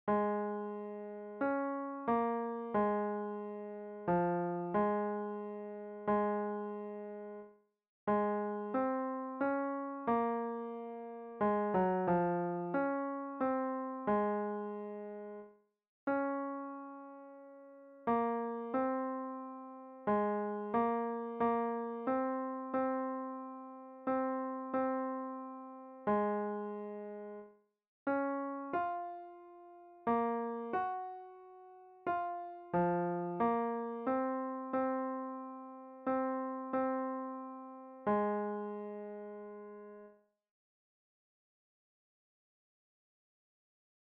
KomDuLjuva-Lead.mp3